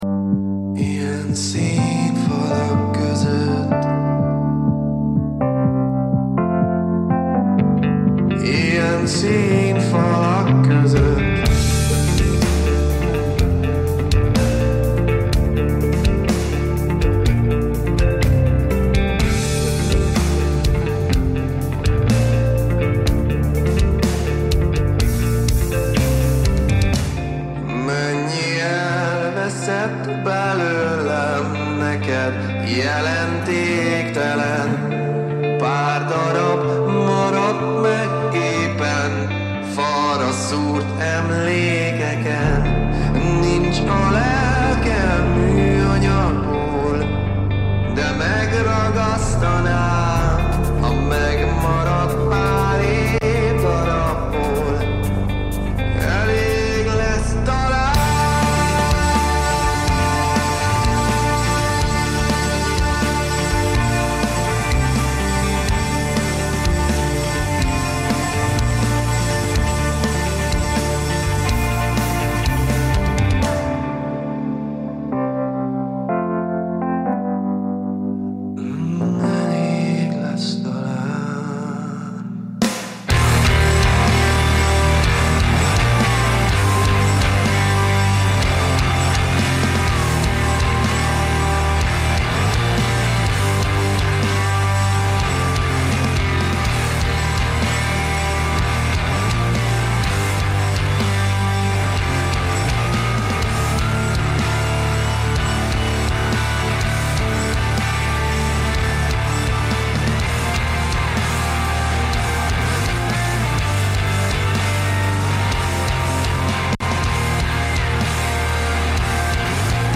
Jeden Donnerstag stellen wir euch regionale Musik vor und scheren uns dabei nicht um Genregrenzen.
Regionale Musik Dein Browser kann kein HTML5-Audio.